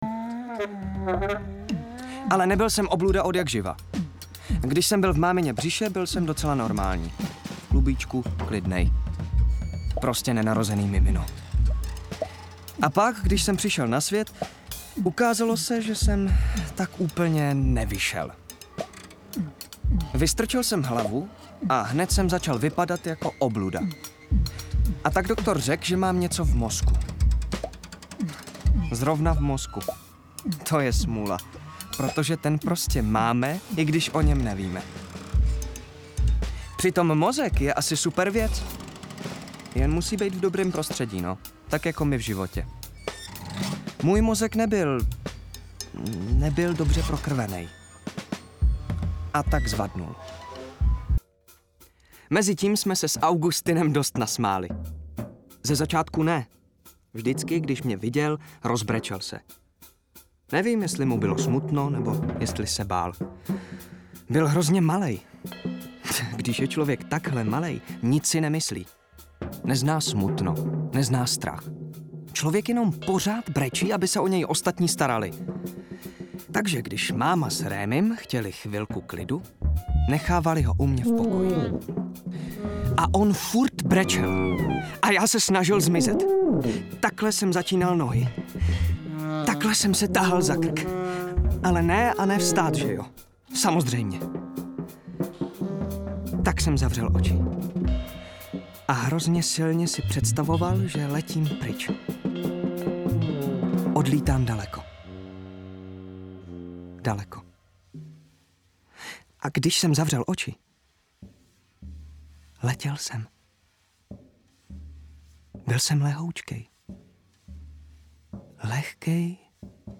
ukázka dabing: